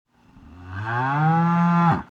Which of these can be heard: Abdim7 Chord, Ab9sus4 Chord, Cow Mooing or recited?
Cow Mooing